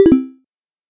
Cancel.ogg